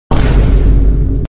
Vine Boom Effect